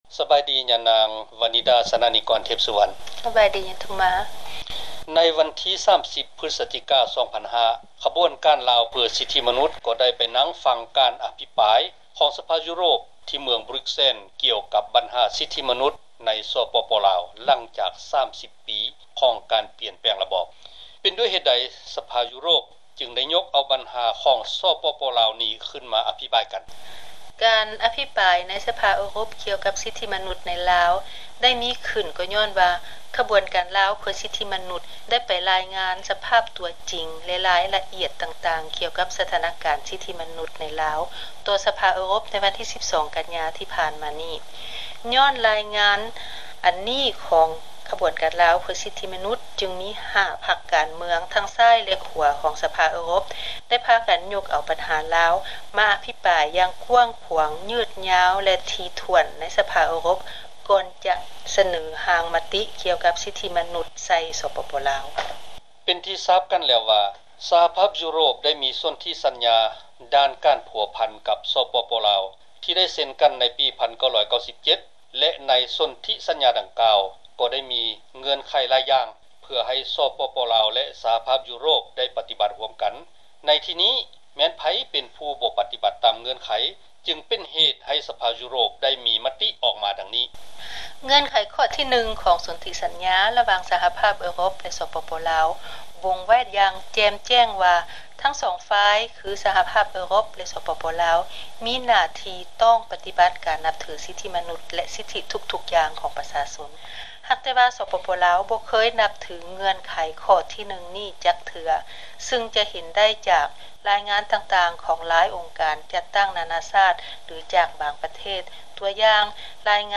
ໃນວັນທີ່ 1 ທັນວາ ປີ 2005 ນີ້ ສະພາຢູໂຣບ ໄດ້ລົງມະຕິຮຽກຮ້ອງໃຫ້ ສປປລາວ ປະຕິບັດ ສິດທິມະນຸດ ແລະຫັນປ່ຽນສູ່ລະບອບ ປະຊາທິປະໄຕ ແບບມີຫລາຍ ພັກການເມືອງທັງໃຫ້ມີການປະກັນໃຫ້ ມີຝ່າຍຄ້ານໃນສປປລາວເພື່ອເຮັດໃຫ້ໄດ້ມາ ຊື່ງຄວາມຖືກຕ້ອງ ປອງດອງຊາດ. ສຳພາດ ໂດຍ